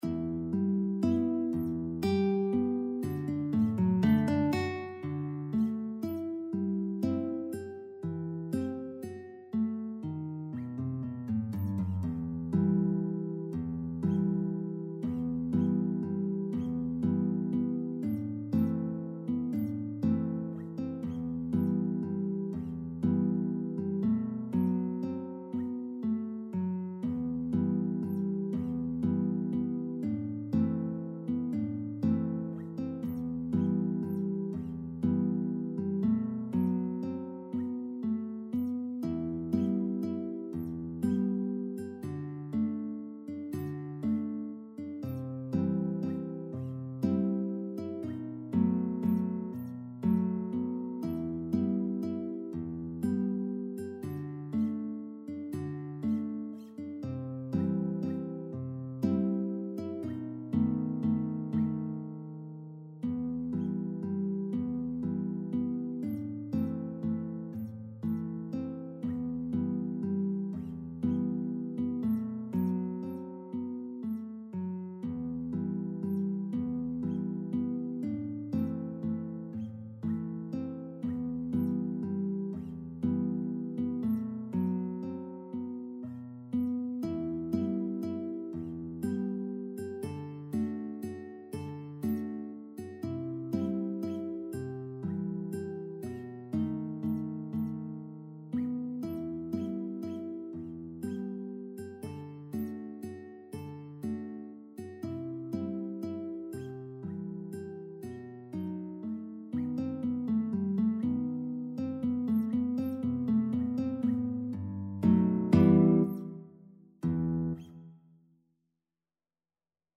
3/4 (View more 3/4 Music)
E minor (Sounding Pitch) (View more E minor Music for Guitar )
Slow Waltz .=40
Guitar  (View more Intermediate Guitar Music)
Traditional (View more Traditional Guitar Music)
la_llorona_GUIT.mp3